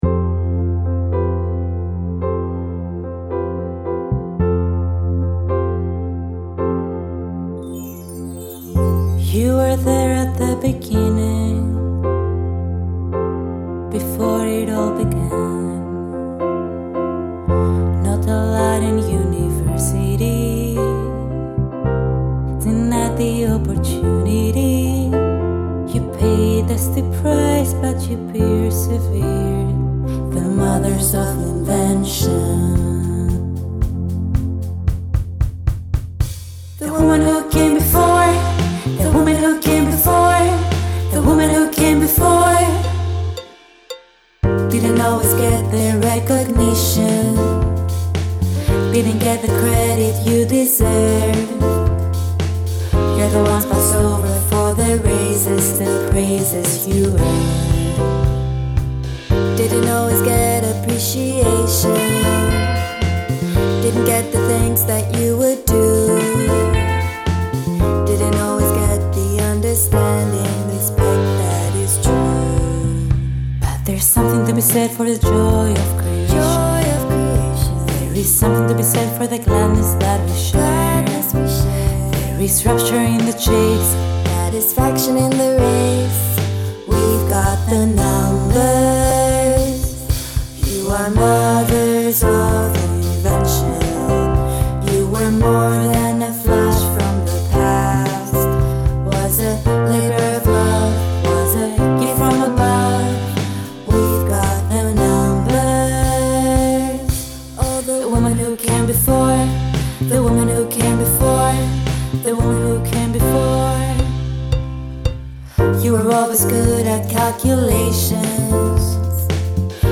A musical about creation of first interactive personal computer at Xerox PARC.